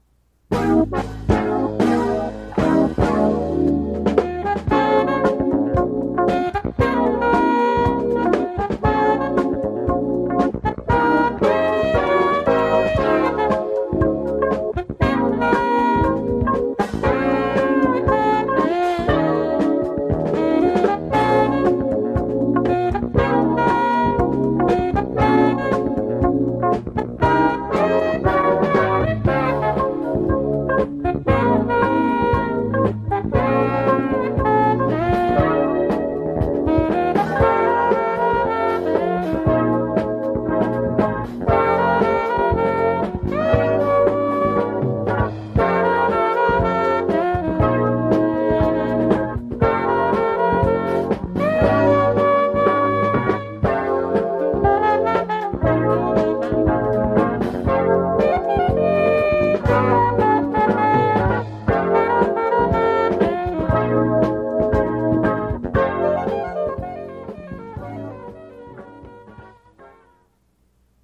audio cassette
reformatted digital